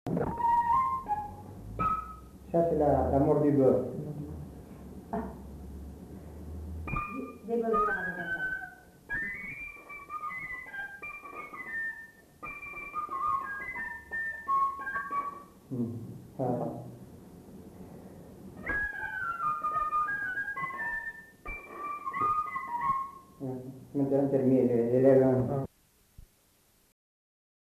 Aire culturelle : Bazadais
Lieu : Bazas
Genre : morceau instrumental
Instrument de musique : fifre